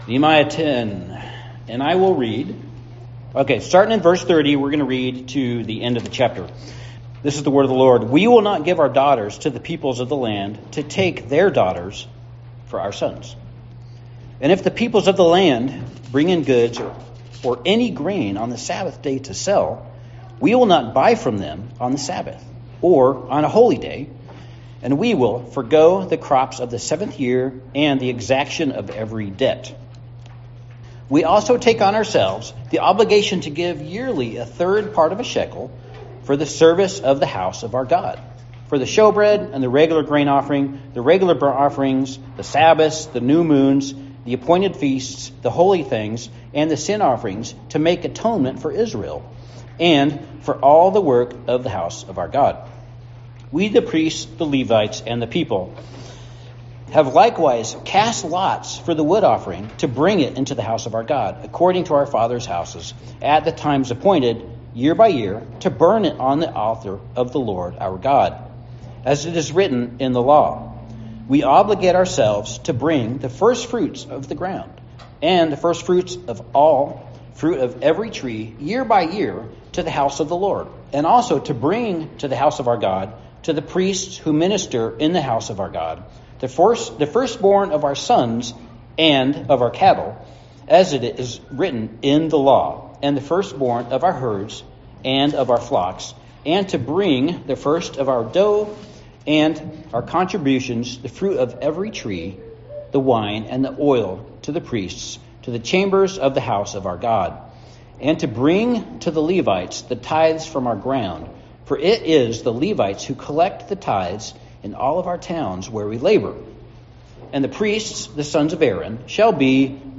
Nehemiah 10:30-39 Service Type: Sunday Service We are a people dedicated to the King.